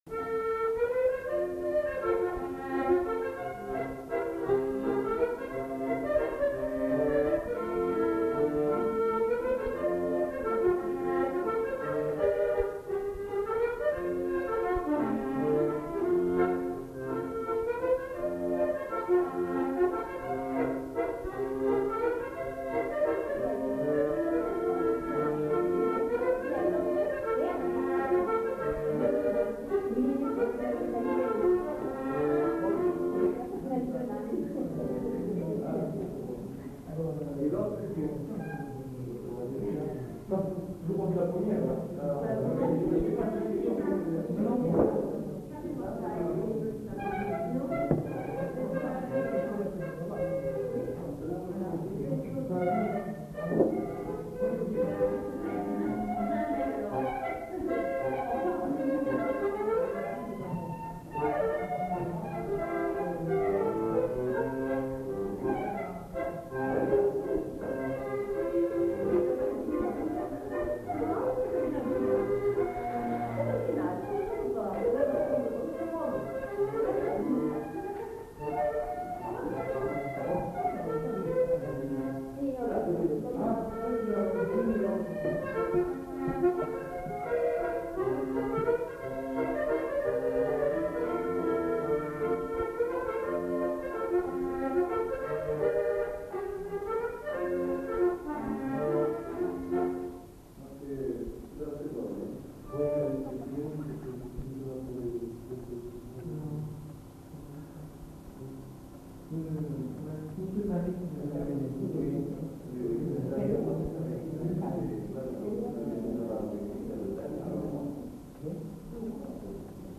enquêtes sonores
Mazurka